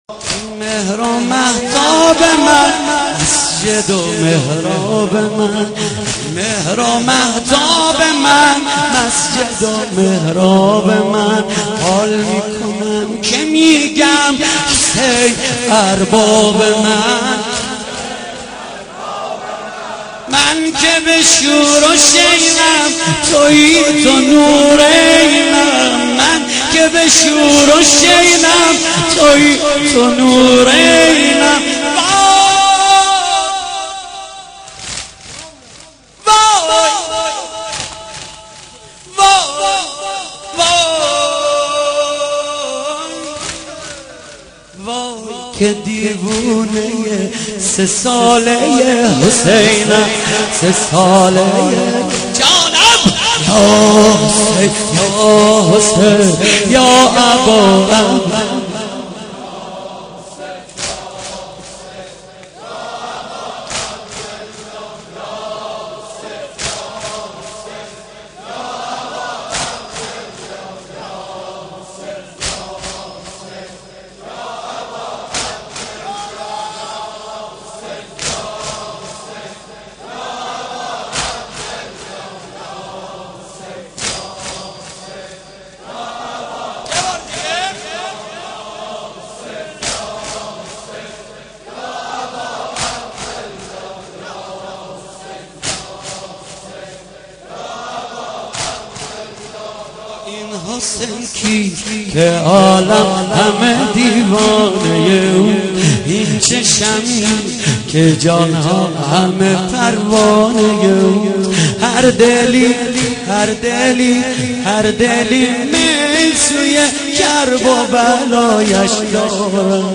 محرم 88 - سینه زنی 8
محرم-88---سینه-زنی-8